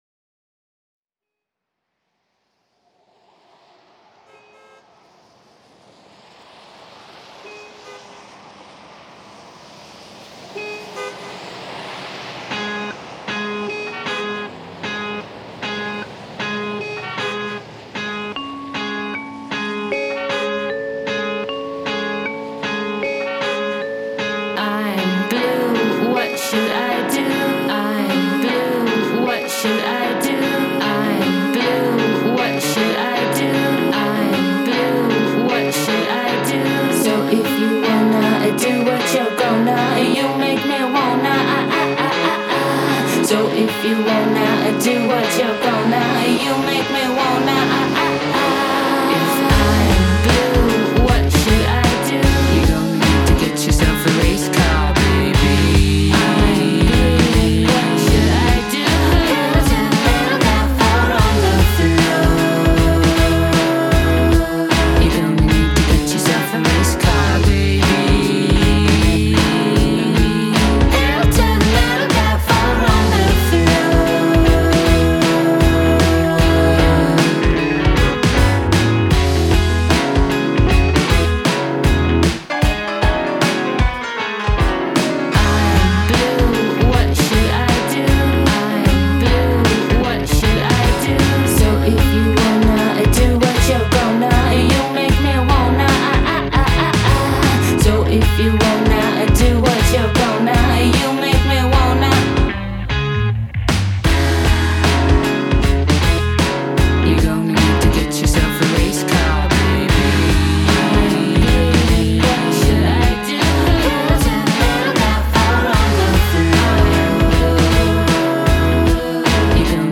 Cheerful doses of mayhem and hooks.